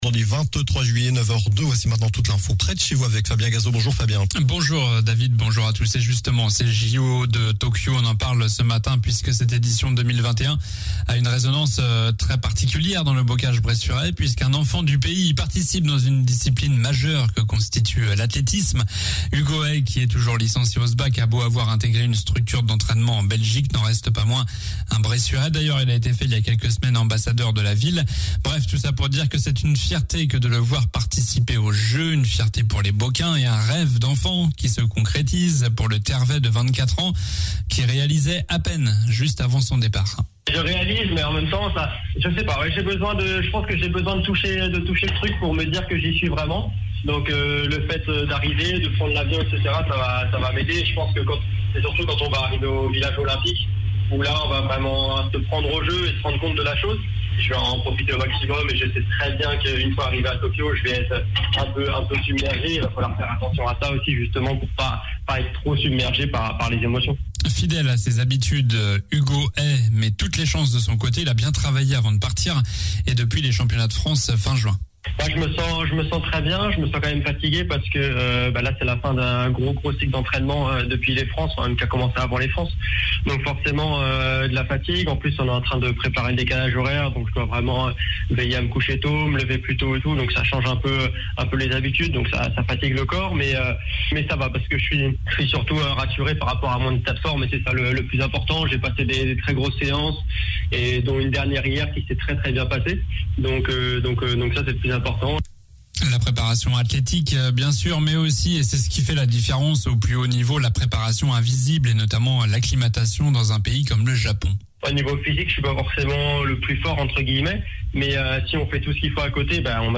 Journal du vendredi 23 juillet (matin)